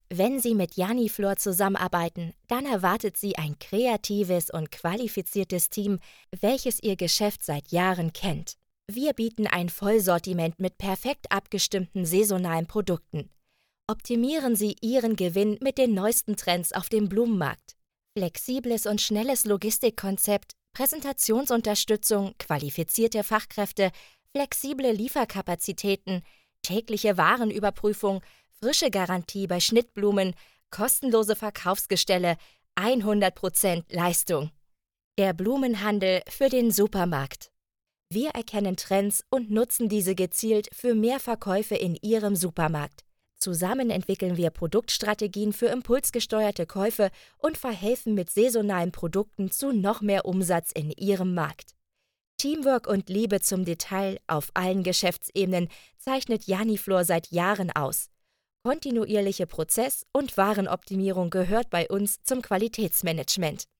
Almanca Seslendirme
Kadın Ses